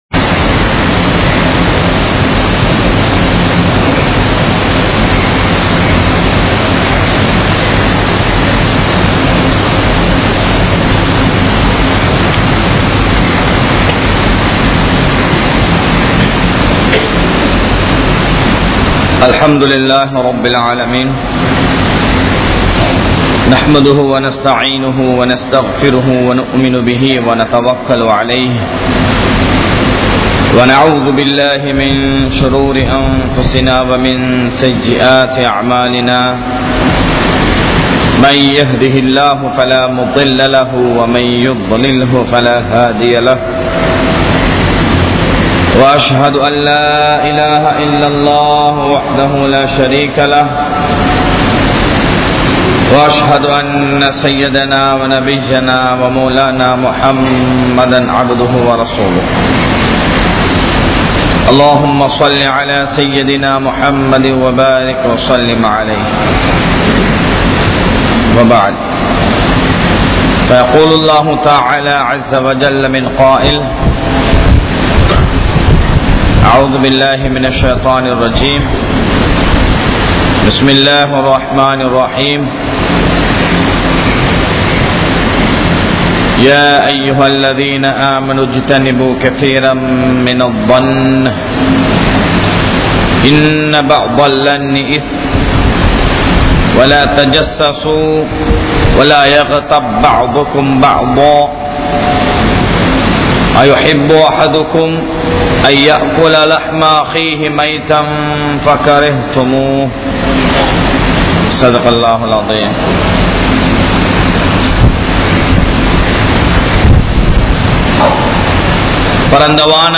Evils of Backbiting(புறம் கூறுவதன் விளைவுகள்) | Audio Bayans | All Ceylon Muslim Youth Community | Addalaichenai